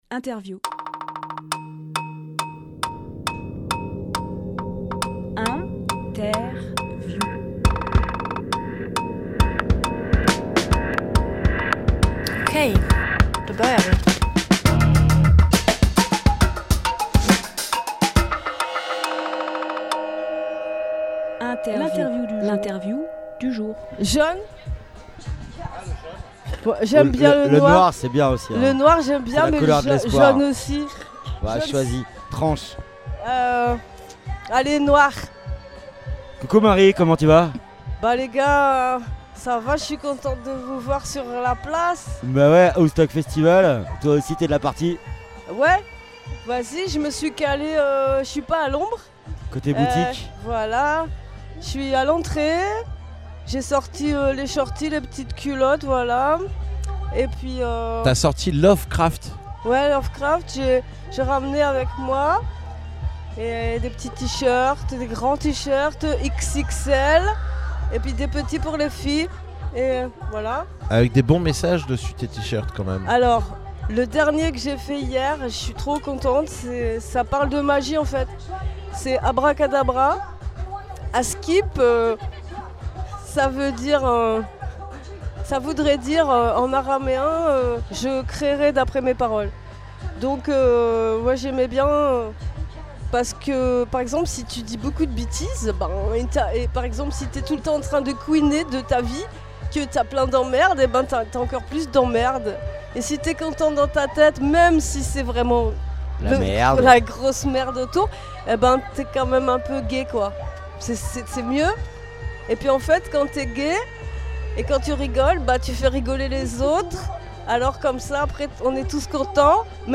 Emission - Interview 1ove Cr4ft, les fringues qui revendiquent Publié le 3 septembre 2022 Partager sur…
Lieu : Aouste-sur-Sye